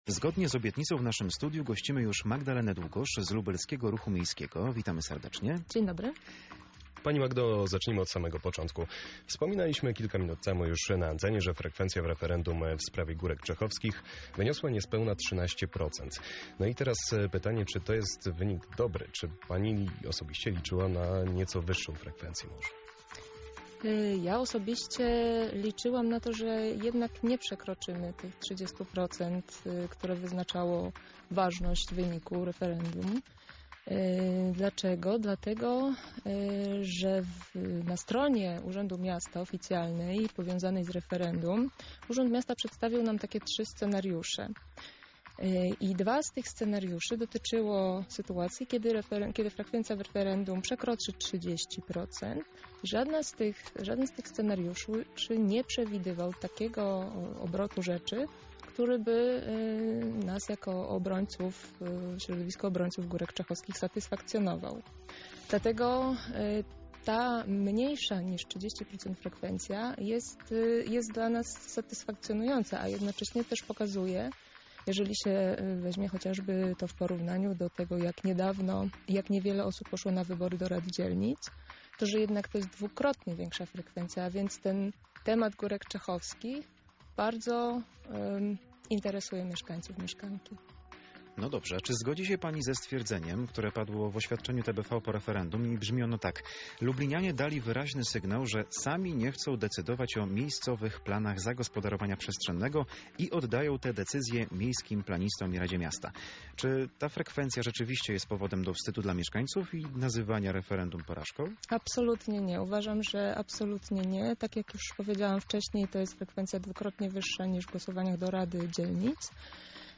W rozmowie poruszyliśmy kwestie kampanii referendalnej, frekwencji oraz przyszłości tego obszaru.